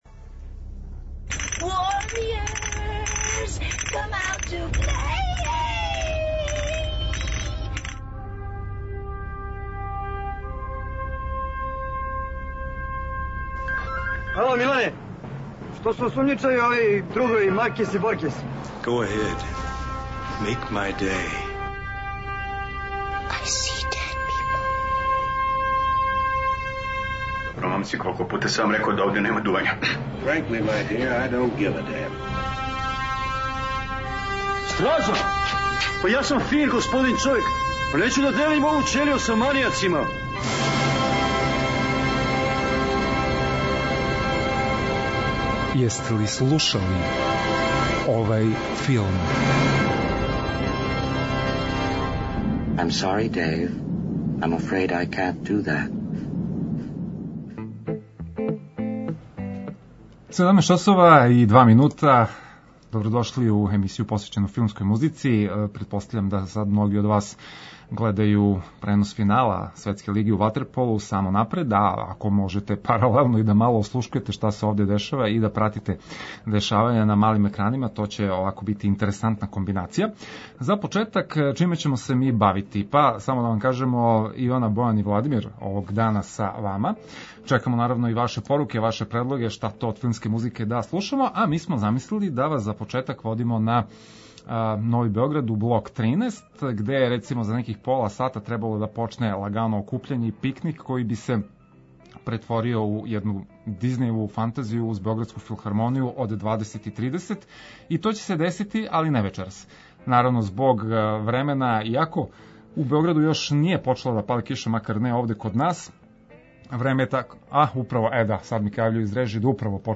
Филмска музика и филмске вести. Заједно ћемо се присетити мелодија које ће нам вратити у сећање сцене из филмова, али и открити шта нам то ново спремају синеасти и композитори.